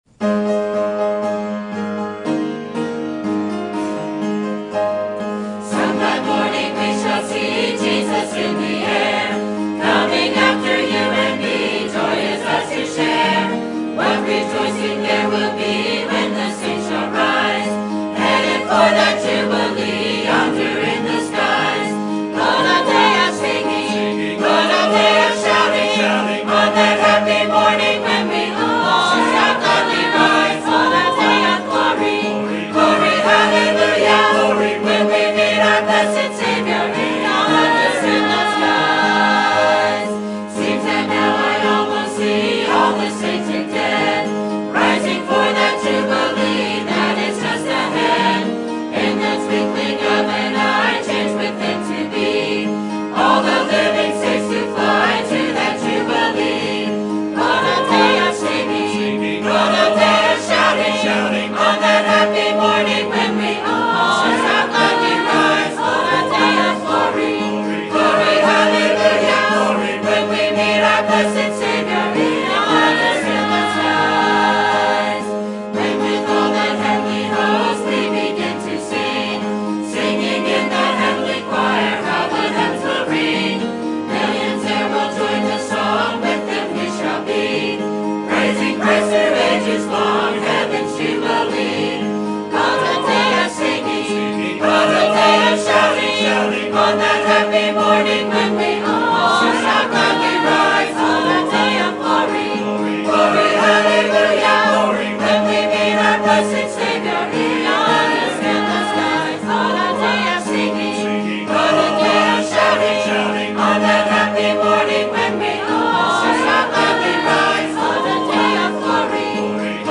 Sermon Topic: Missions Conference 2015 Sermon Type: Special Sermon Audio: Sermon download: Download (22.72 MB) Sermon Tags: Genesis Best Missions Sacrifice